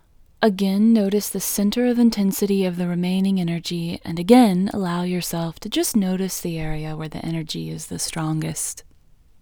IN Technique First Way – Female English 14